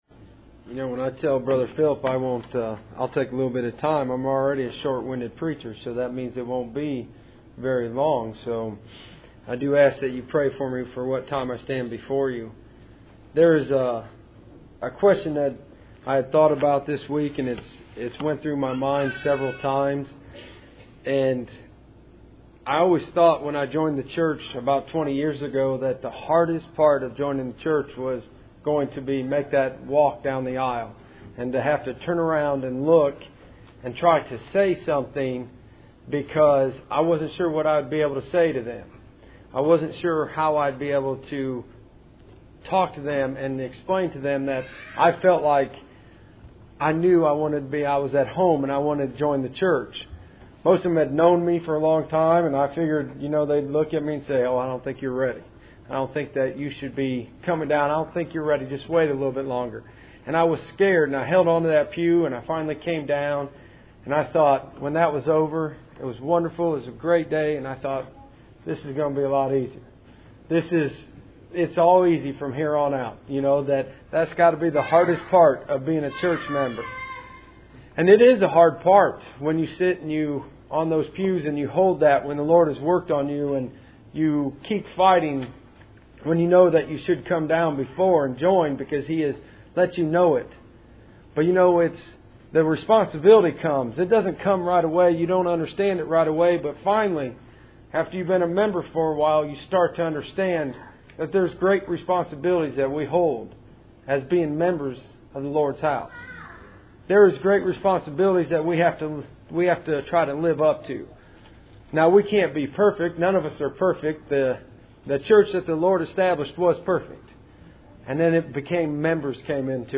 Passage: James 4:17, Matthew 25:14-15 Service Type: Cool Springs PBC Sunday Evening %todo_render% « Looking at Salvation and Patiently Waiting Mary